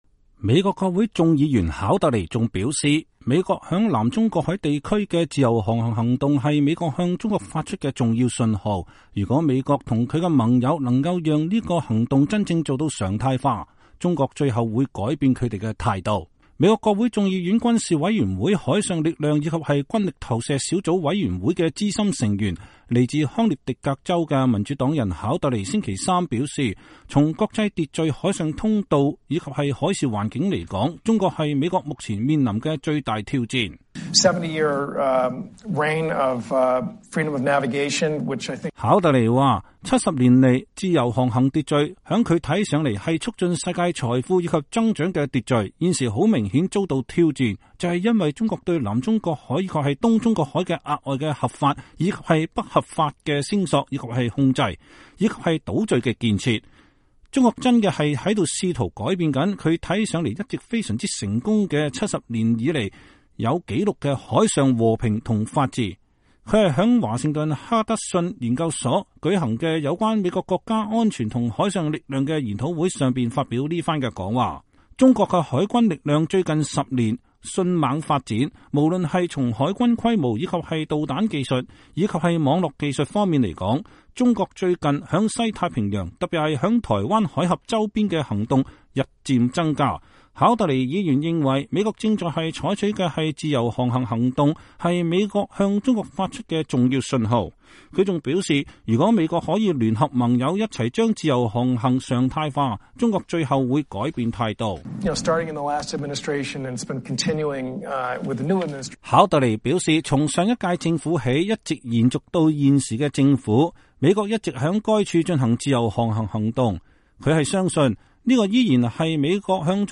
他是在華盛頓哈德遜研究所舉行的有關美國國家安全和海上力量的研討會說這番話的。